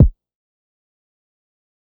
Rack Kick2.wav